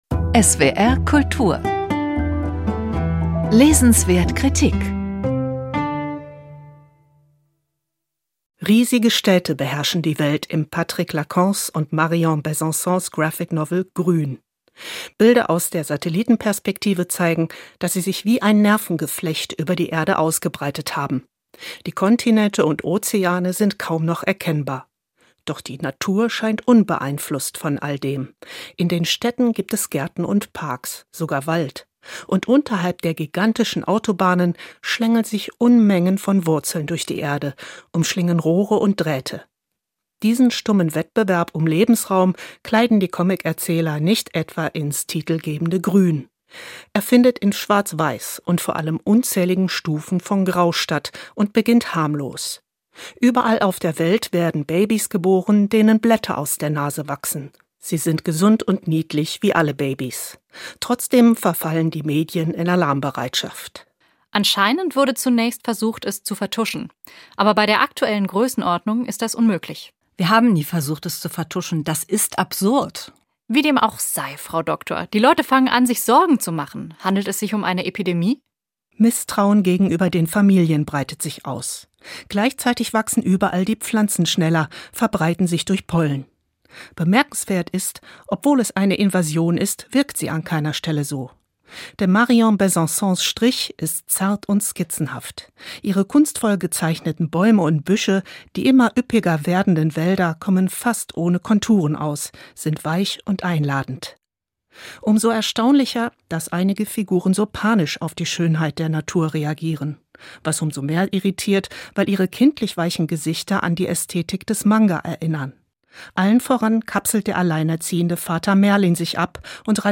Rezension von